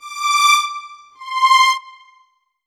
Synth 01.wav